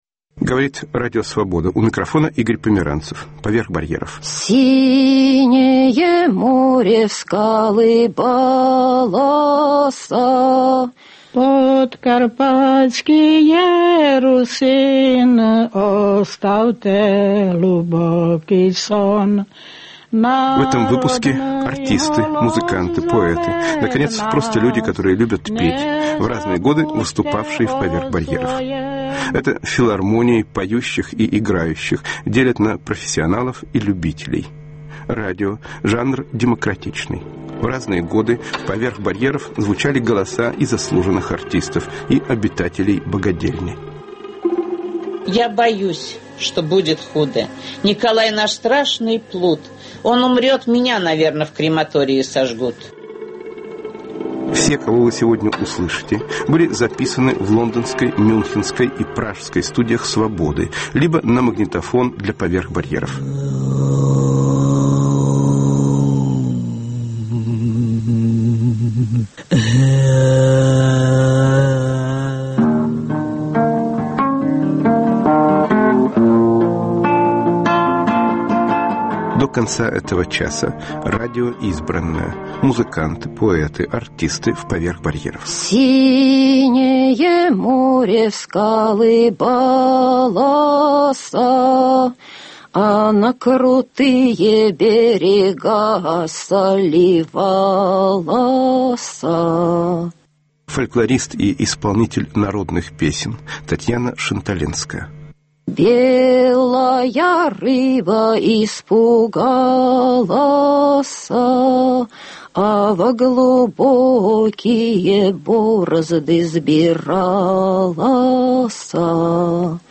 Музыканты, артисты, поэты, записанные в разные годы в лондонской, мюнхенской и пражской студиях радио "Свобода": Дмитрий Пригов (1940-2007), Саинхо Намчилак, Тимур Кибиров, Людмила Петрушевская, Илья Кормильцев (1959-2007), Валентина Пономарёва и др.